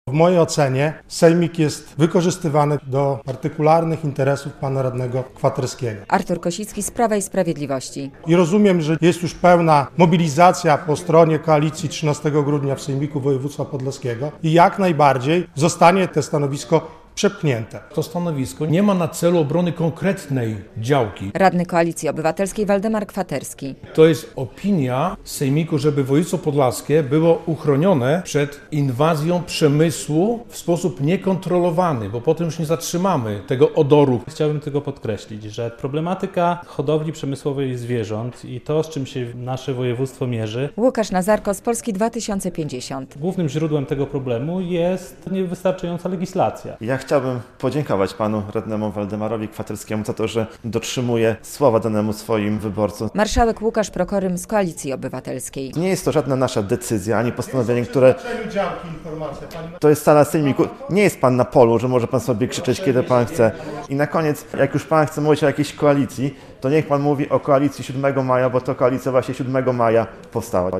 Budowa dużej chlewni w gminie Giby wstrzymana przez Regionalną Dyrekcję Ochrony Środowiska - relacja